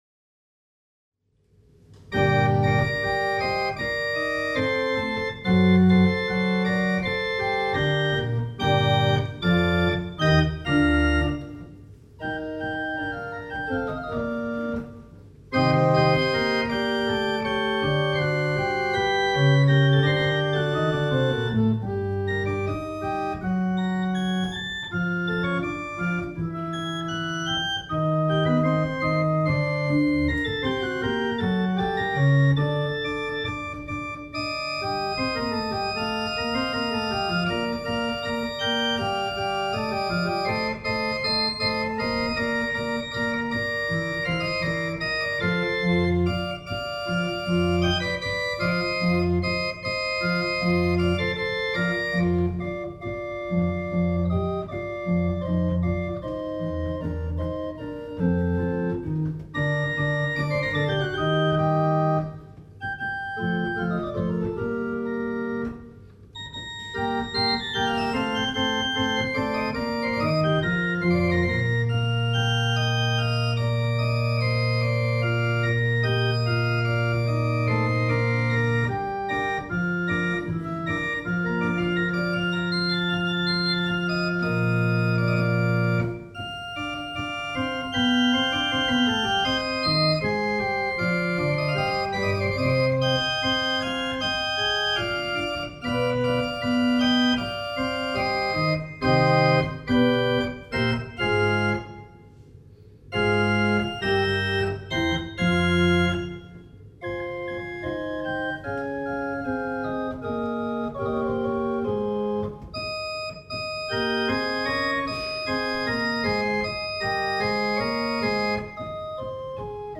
Orgelschätze Logo Orgelschätze Logo Die kleine Orgel wurde im Jahr 1979 als gebrauchtes
Orgel gibt wirklich einen lebendigen Klang von sich.
Ausschnitte aus dem Konzert: